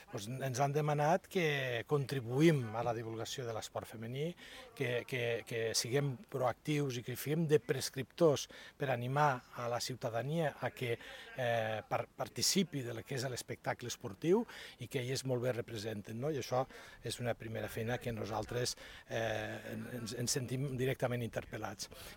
Tall de veu de l'alcalde, Fèlix Larrosa, que qualifica les noies de "referents" (343.5 KB) ©AjuntamentdeLleida Tall de veu: L'alcalde es compromet a fomentar la difusió sobre l'equip, perquè la ciutadania les conegui (314.6 KB) ©AjuntamentdeLleida